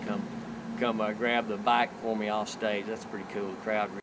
males/6.wav